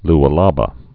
(lə-läbə)